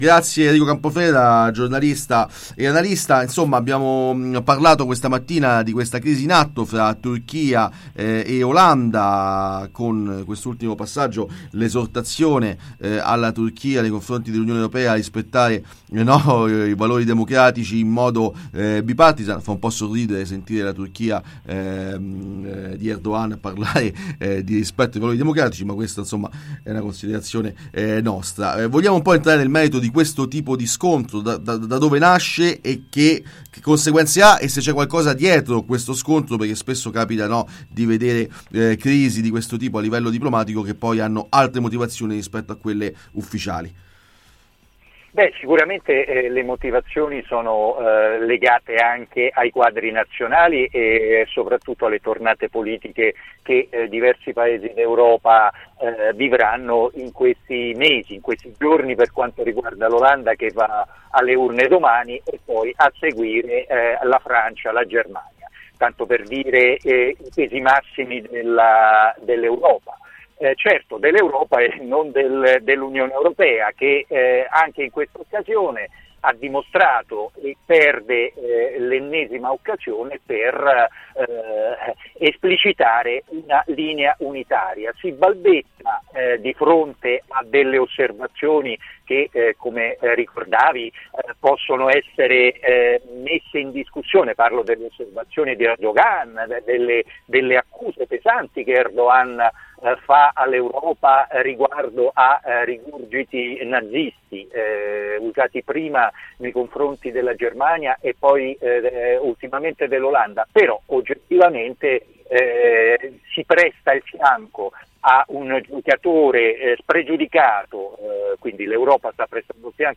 Tra Turchia, Olanda (e UE) è crisi. Intervista